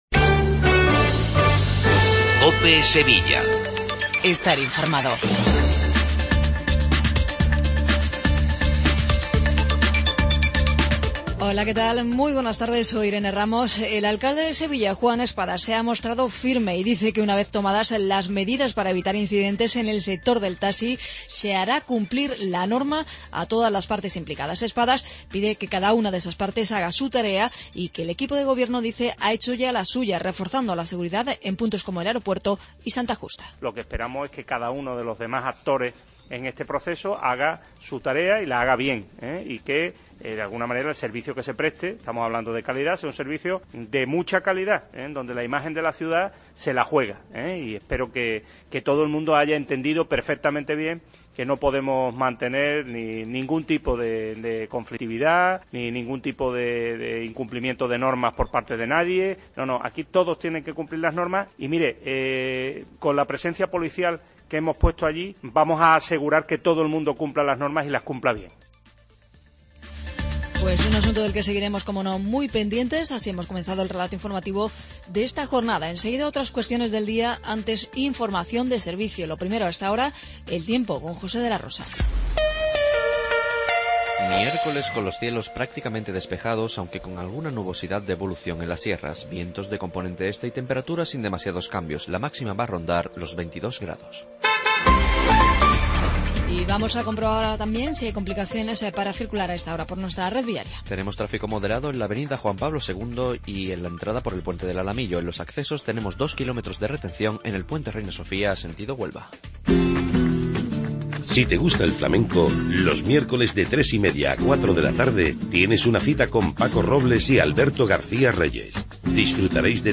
INFORMATIVO LOCAL MEDIODIA COPE SEVILLA